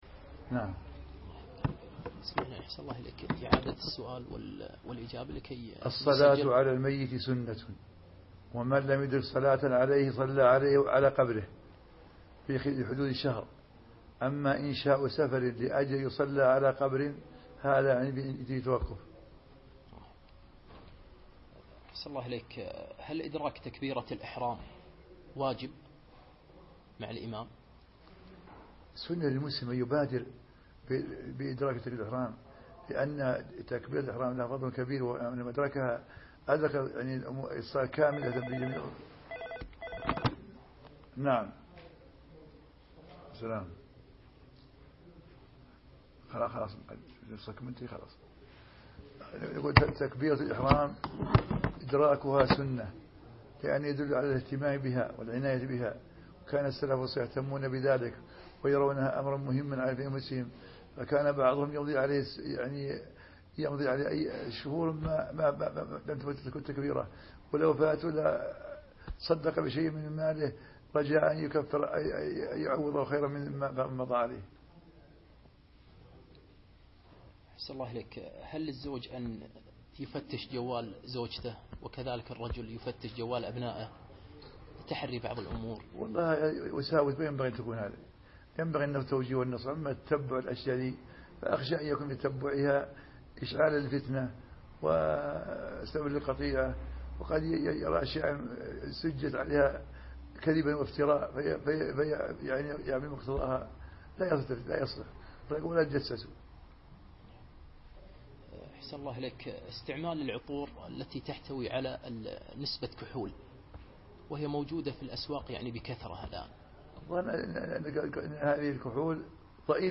لقاء مفتوح مع مفتي المملكة الشيخ عبدالعزيز آل الشيخ